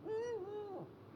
eagle1.ogg